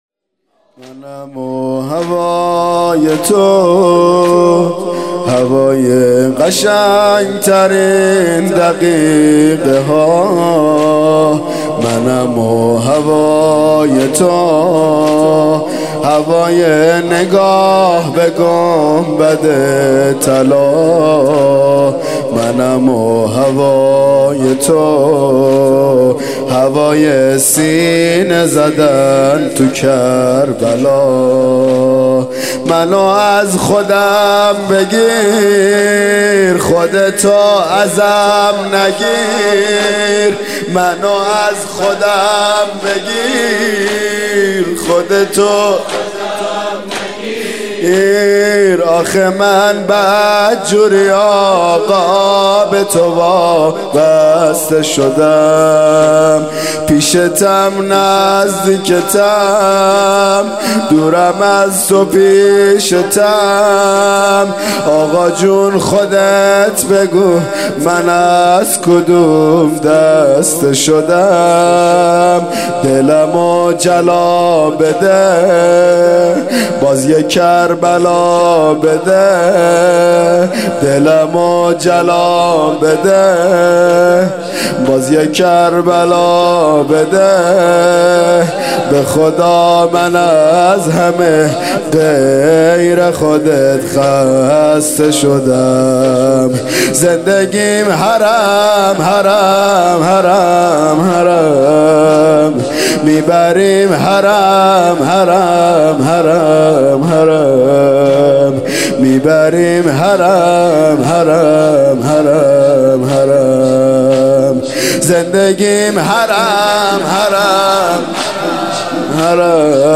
مراسم هفتگی 1395